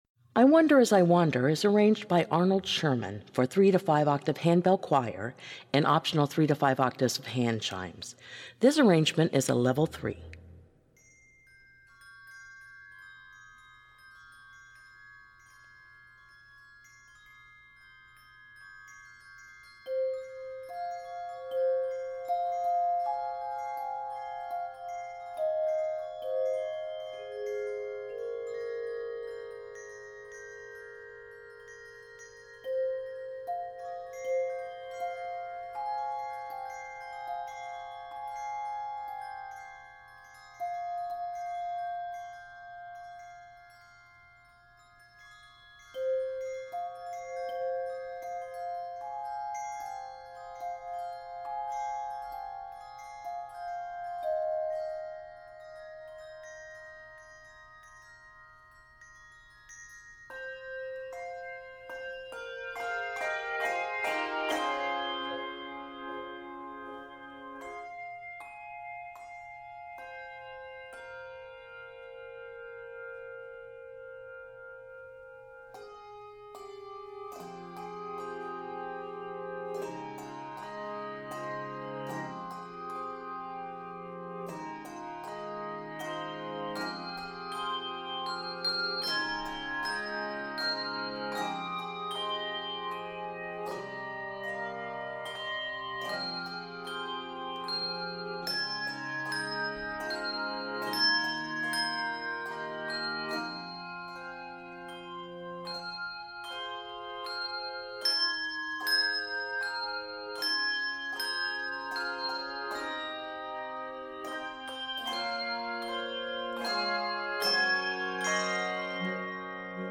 Emotionally powerful
Octaves: 3-5